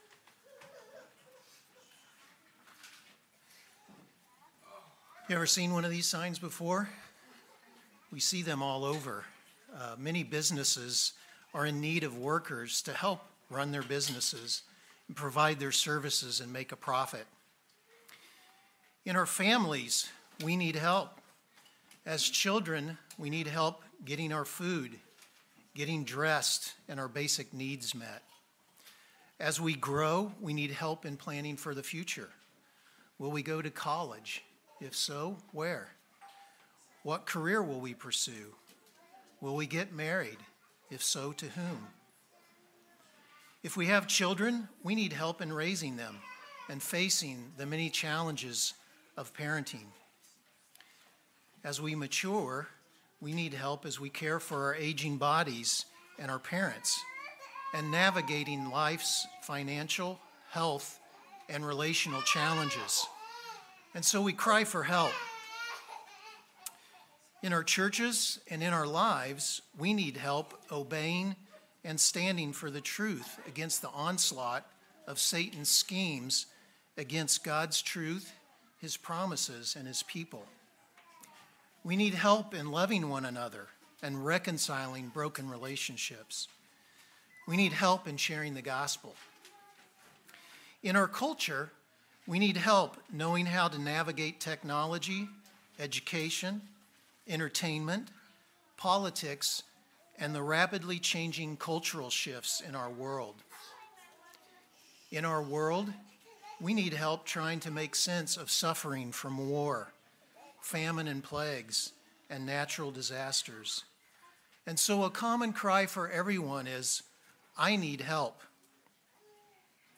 Sermon